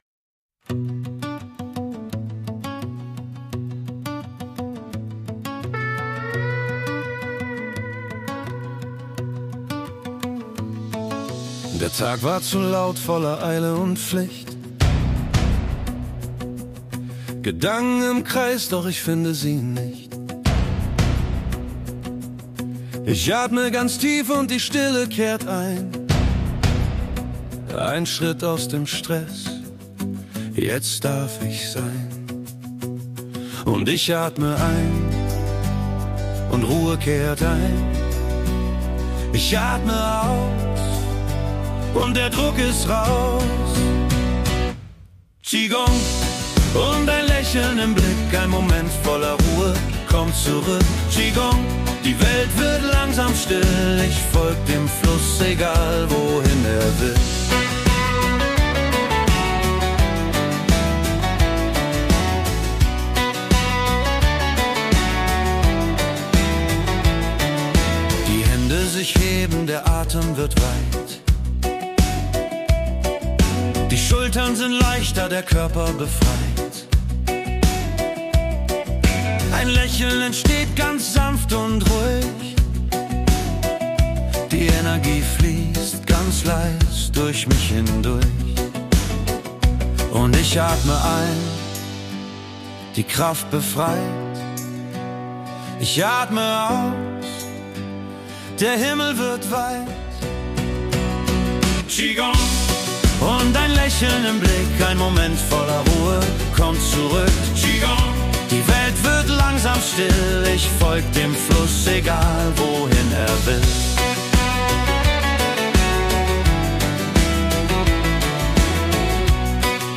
Komposition mit Suno AI